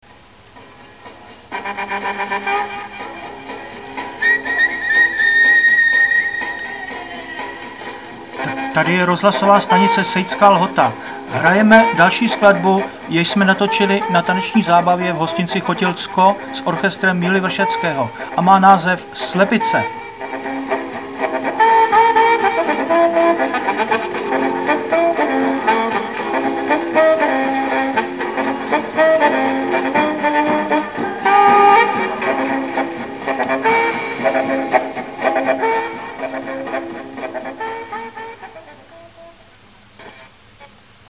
Hlavní náplň hudba na přání a někdy živá hudba kdy interpret na kytaru stál před mikrofonem.